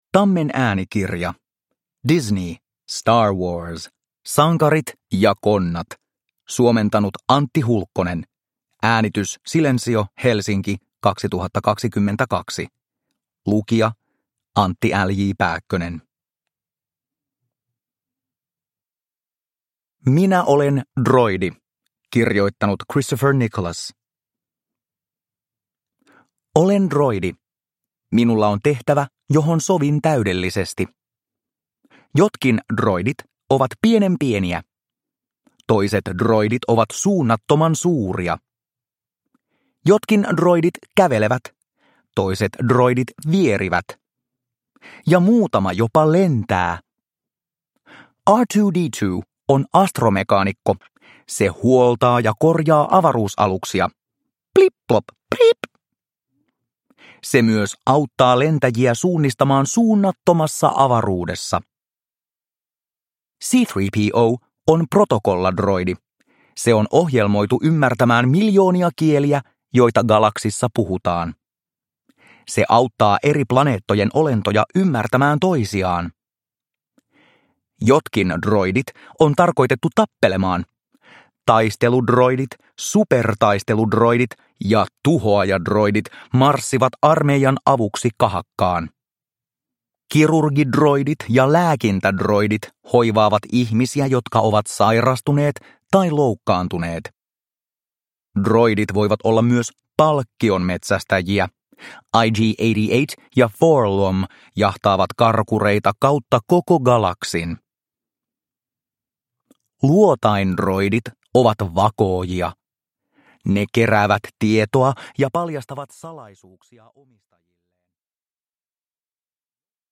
Star Wars. Sankarit ja konnat – Ljudbok – Laddas ner